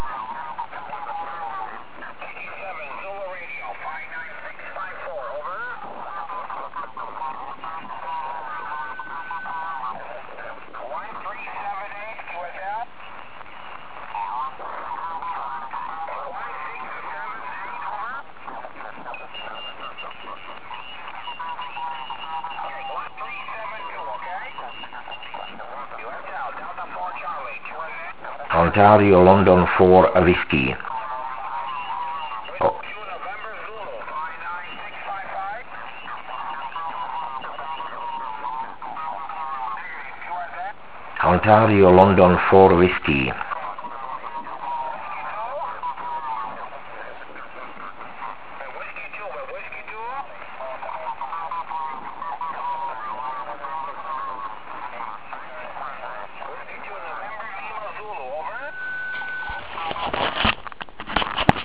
(V dalším jsou záznamy z tohoto závodu)